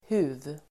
Uttal: [hu:v]